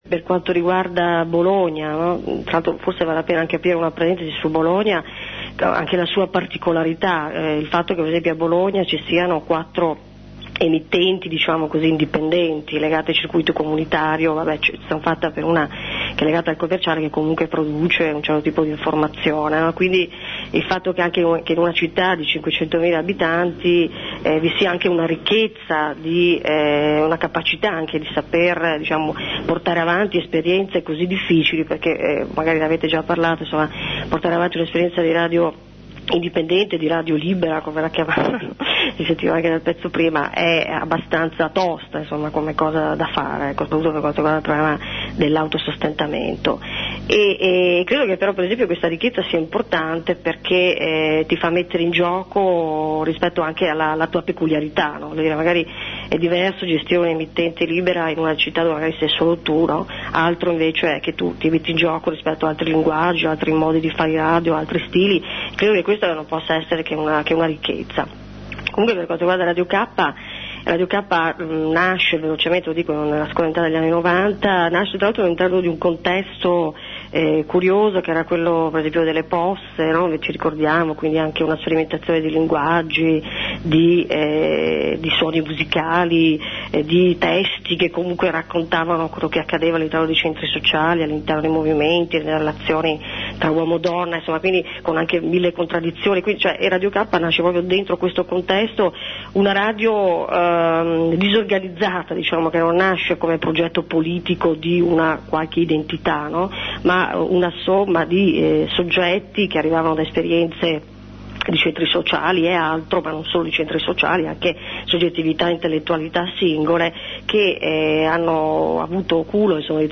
Intervista a Radio Kappa